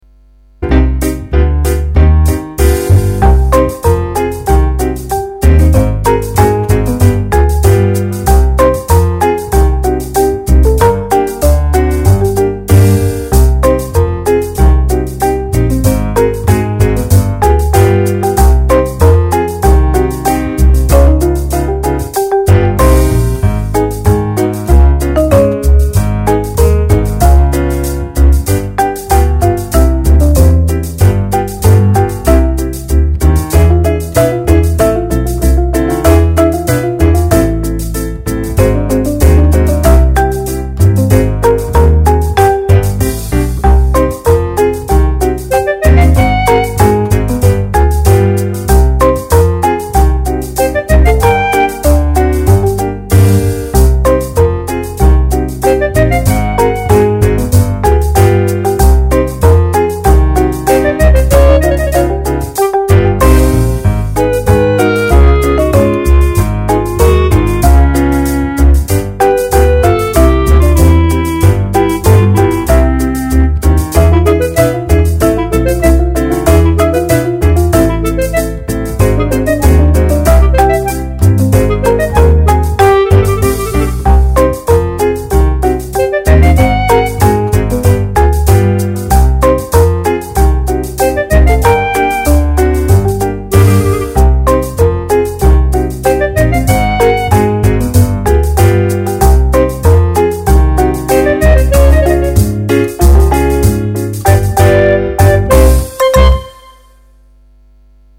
Version instrumentale :